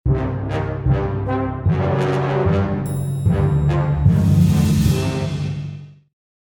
ttr_s_ara_spookyCurse.ogg